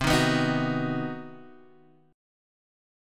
C Minor Major 7th Flat 5th
CmM7b5 chord {x 3 1 4 4 2} chord